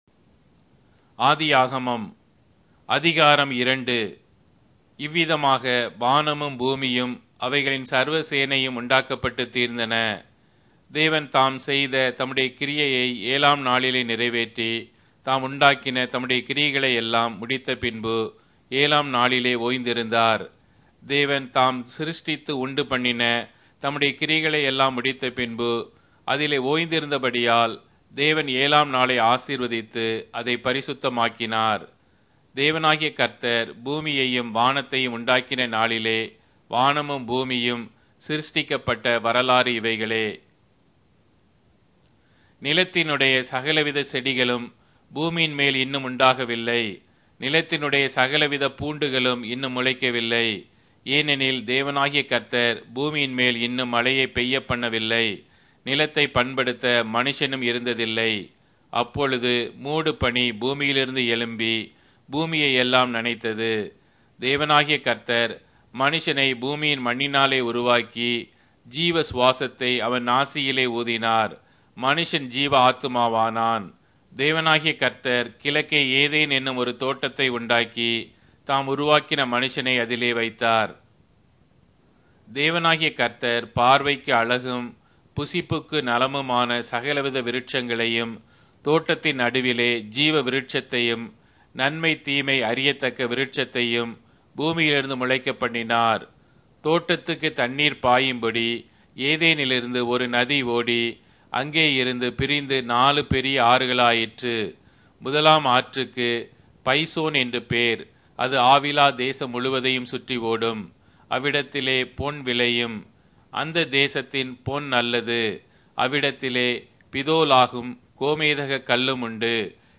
Tamil Audio Bible - Genesis 28 in Erven bible version